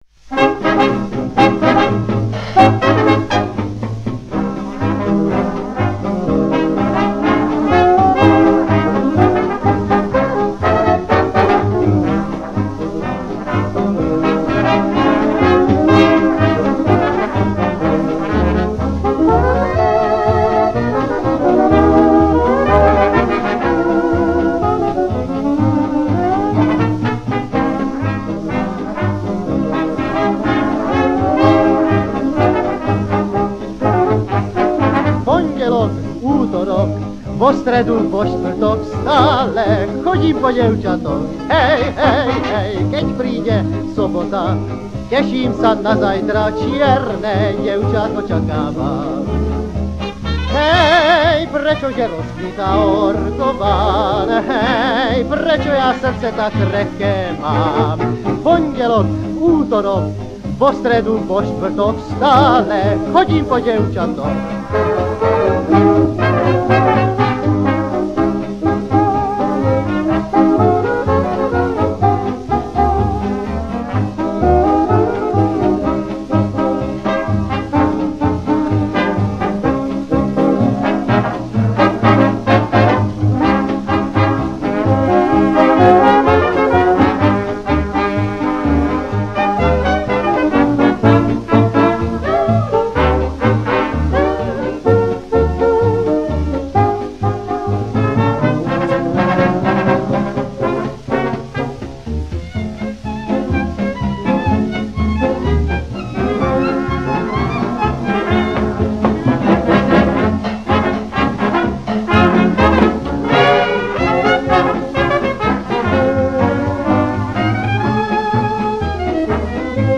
Dátum a miesto nahrávania: 28.4.1937, Studio Rokoska, Praha
Slow-fox
Praha Popis Mužský spev so sprievodom orchestra.